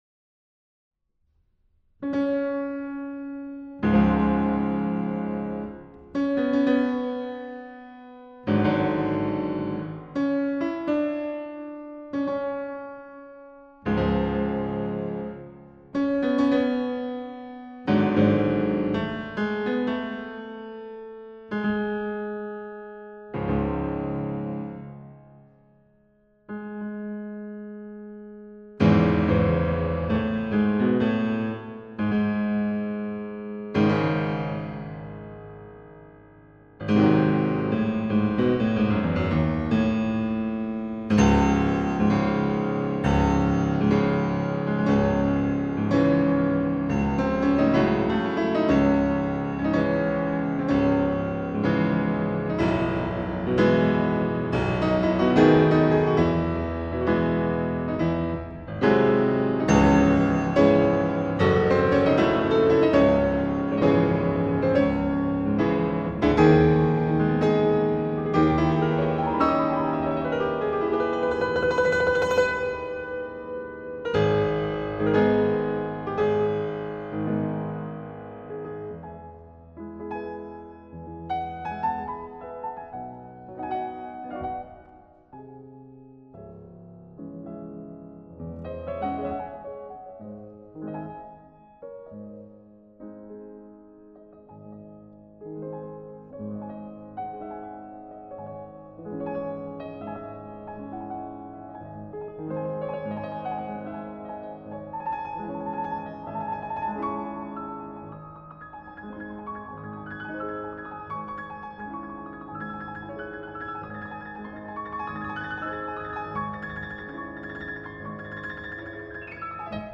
0301-钢琴名曲升C小调第二匈牙利狂想曲.mp3